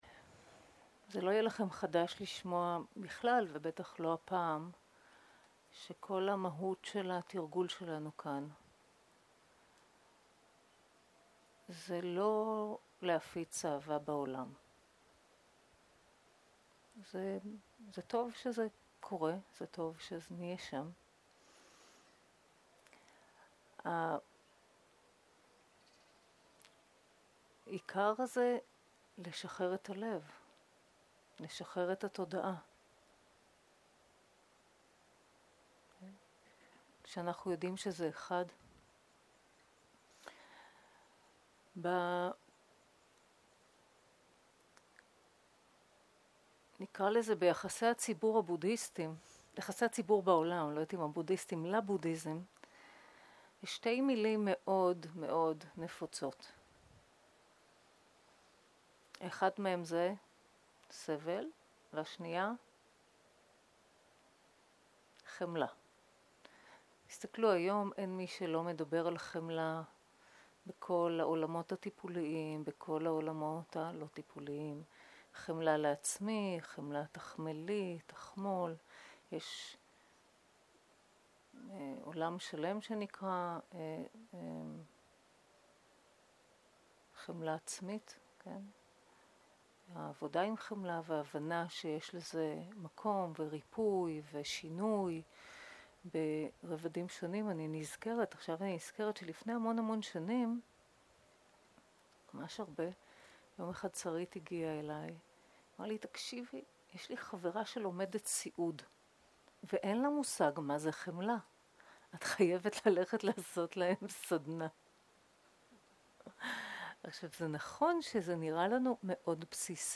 שיחת דהרמה
סוג ההקלטה: שיחות דהרמה